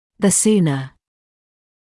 [ðə ‘suːnə][зэ ‘суːнэ]чем скорее; тем скорее